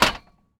metal_hit_small_09.wav